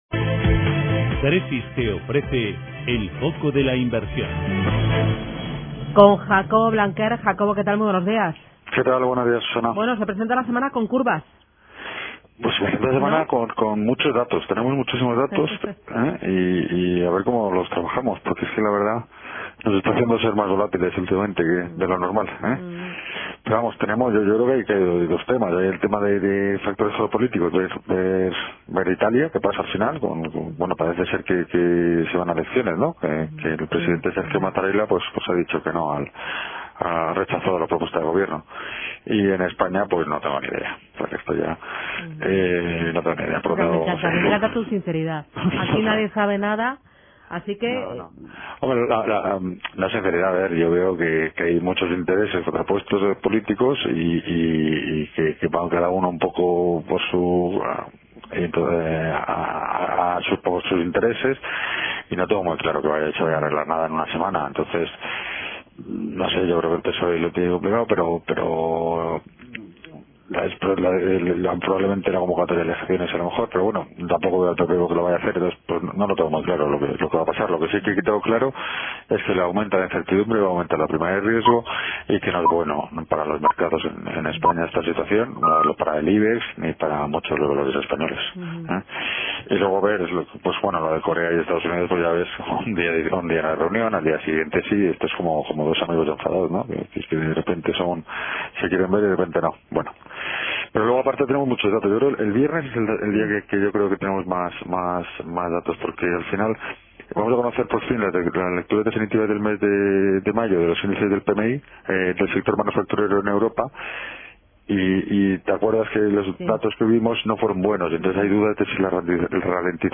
En la radio
Comentario semanal (29/05/2018)